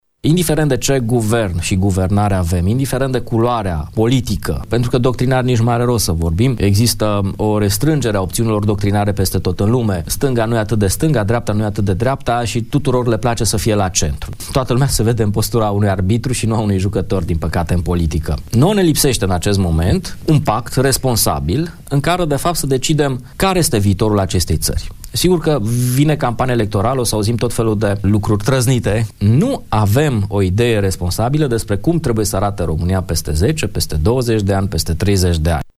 analistul politic